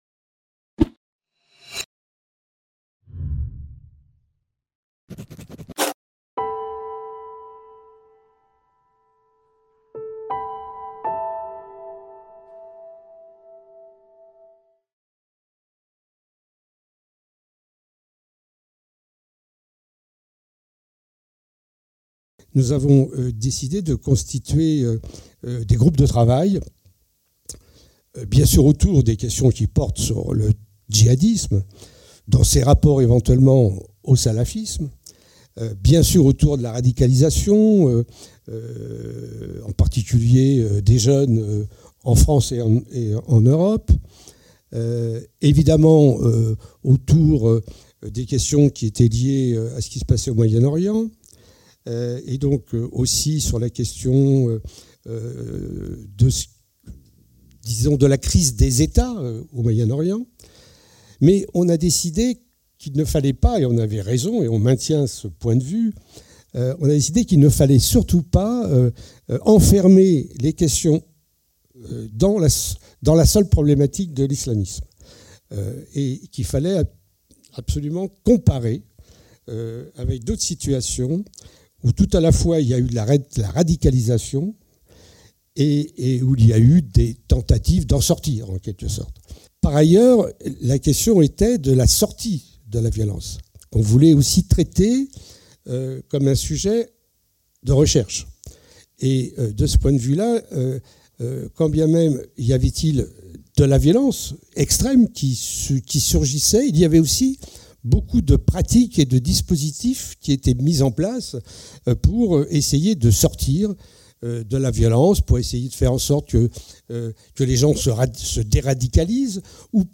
Colloque international Violence et sortie de la violence en Afrique méditerranéenne et subsaharienne FMSH – UIR (Chaire CSFR)